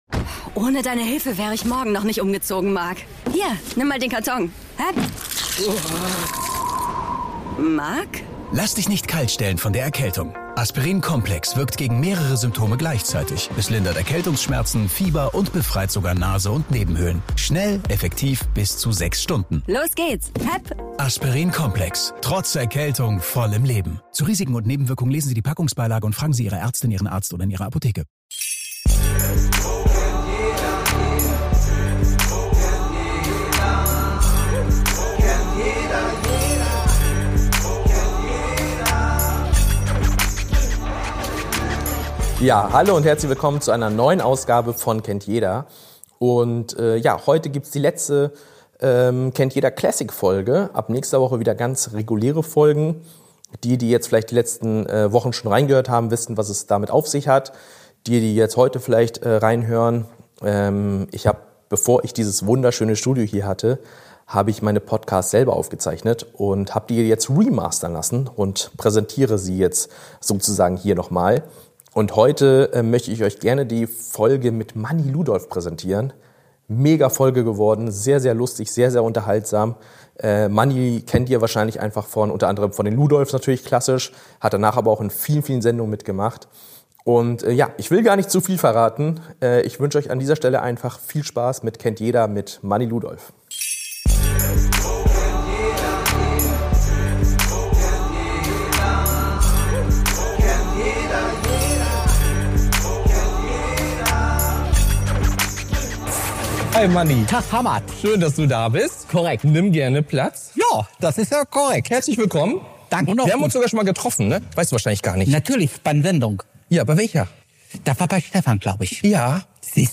Ein Gespräch, das genauso herzlich wie unterhaltsam ist.